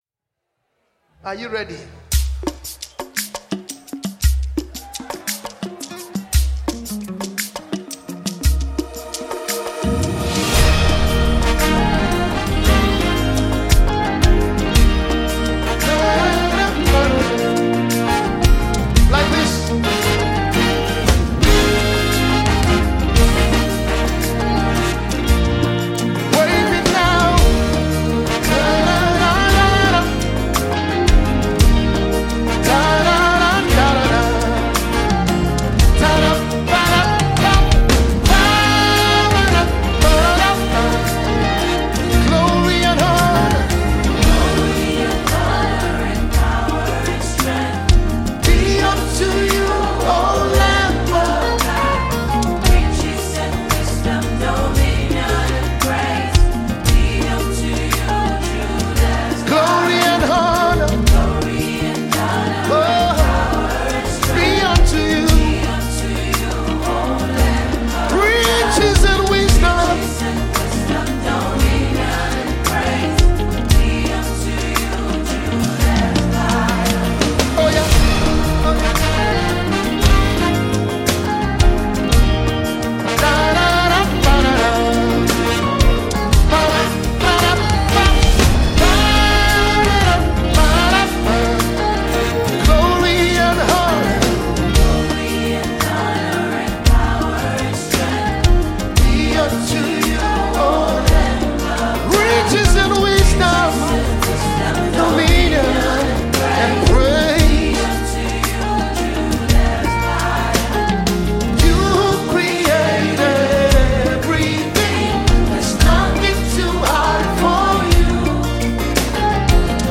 an award-winning Nigerian gospel music sensation and